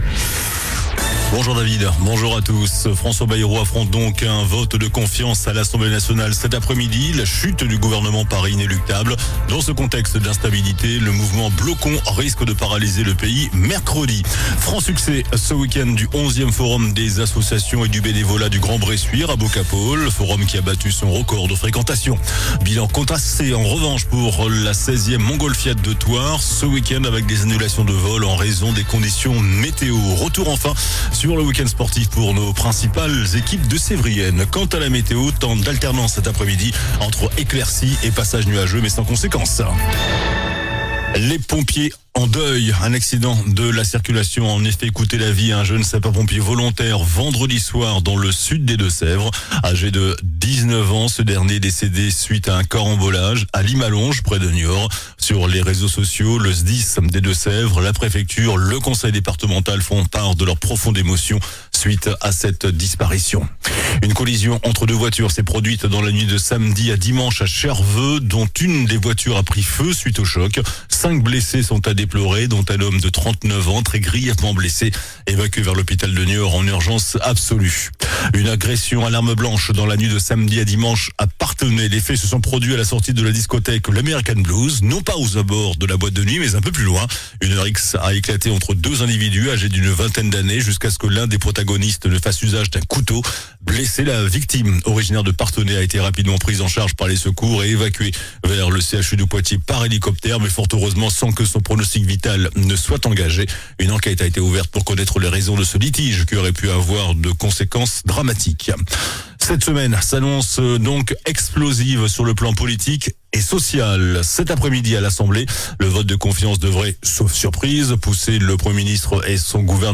JOURNAL DU LUNDI 08 SEPTEMBRE ( MIDI )